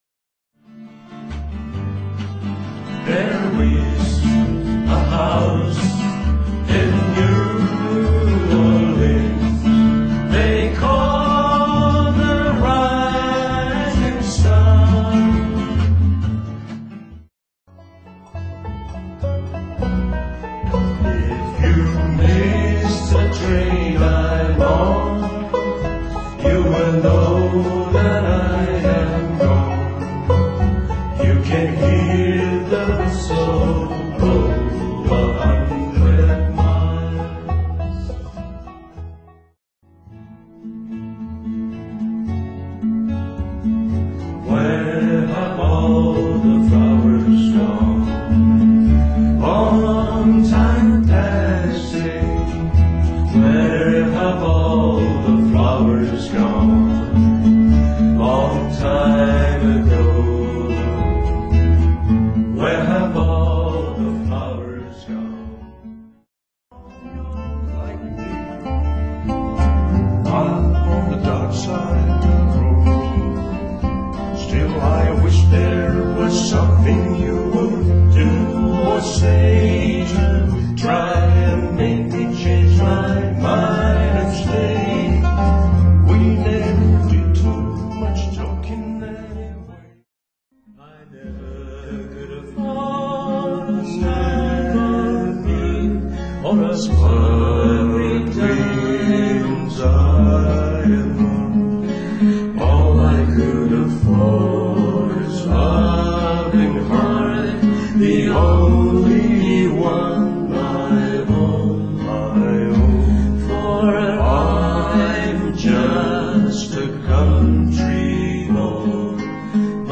３人の歌声はこんな感じ。